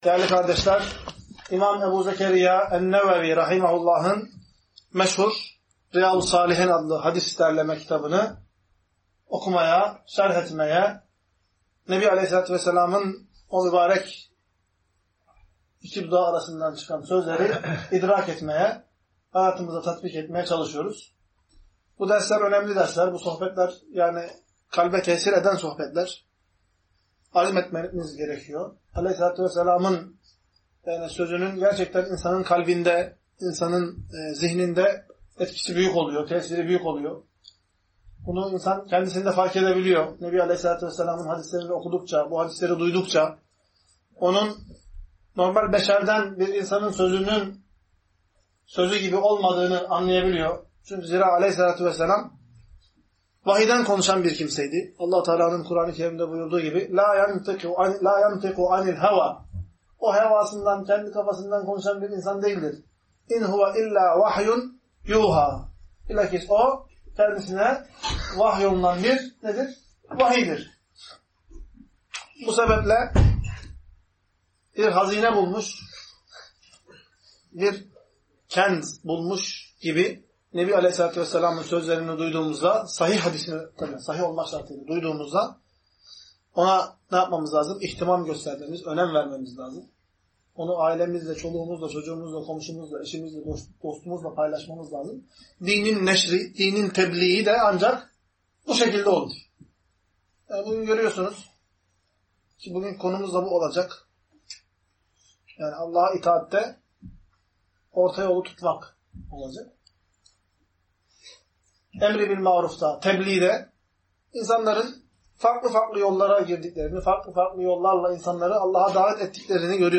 Ders - 14. Bölüm - İbadette Ölçülü olmak